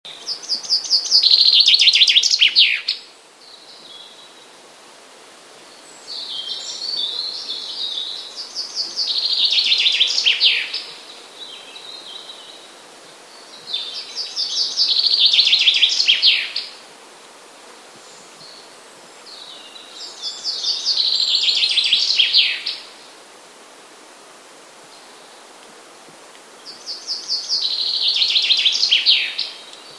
- FRINGILLA COELEBS
piosenek zięby (L. 6 lipca 2014 r.), na którym jest 5 piosenek. to nagranie przedstawiam w 4-krotnym zwolnieniu i obniżeniu o dwie oktawy. Łatwo zauważyć, że modulacja sylab jest inna niż w przykładzie poprzednim.
W powyższym przykładzie sylaba D jest podwojona, wysoka i krótka, nie ma charakteru wyraźnie opadającego jak w przykładzie poprzednim.